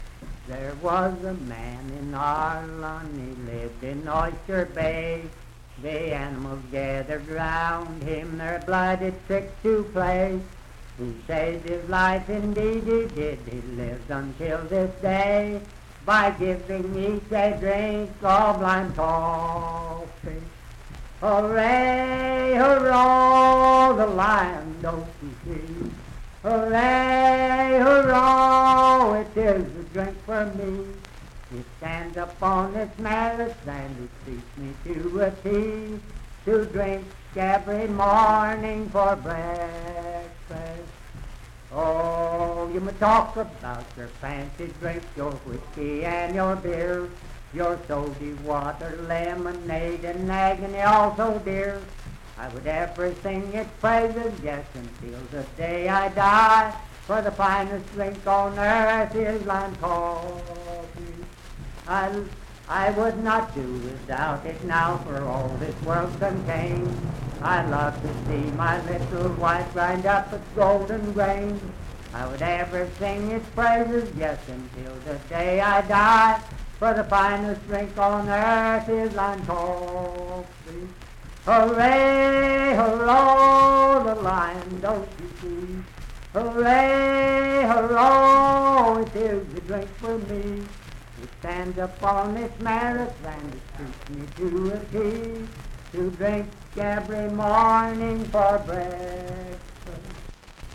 Unaccompanied vocal music and folktales
Verse-refrain 3(4) & R(4).
Voice (sung)